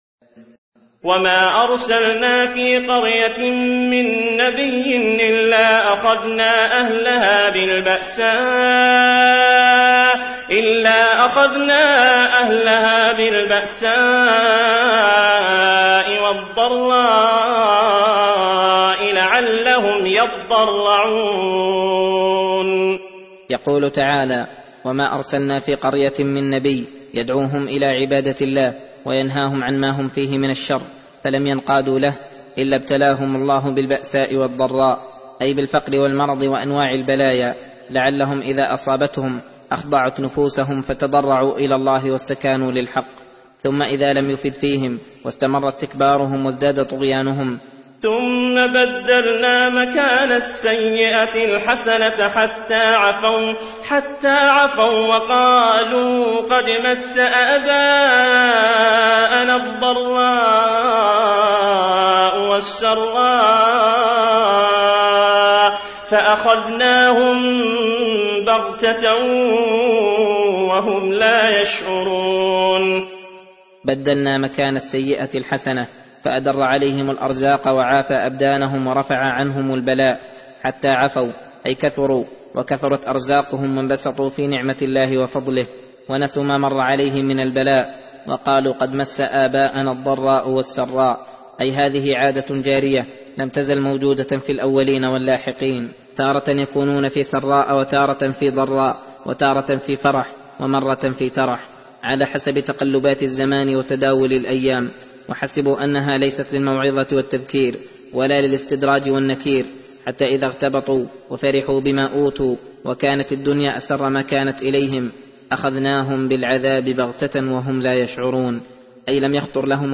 درس (6) : تفسير سورة الأعراف : (94-126)